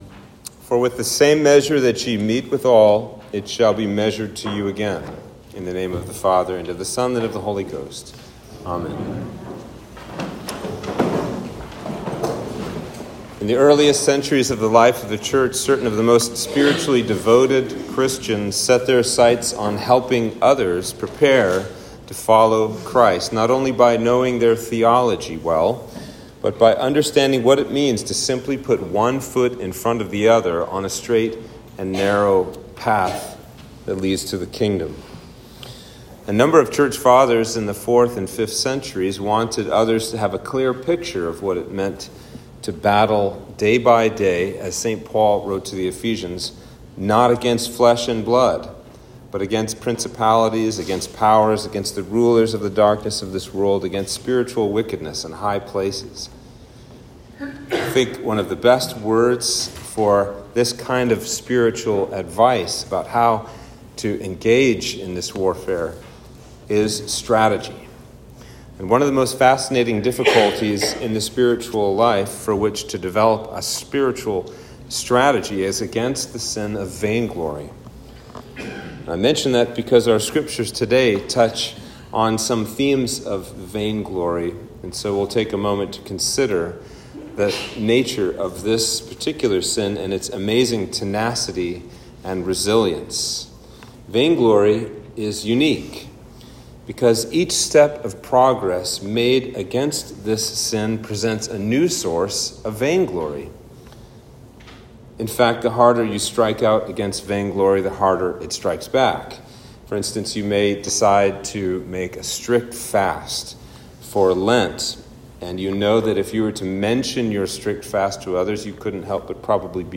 Sermon for Trinity 4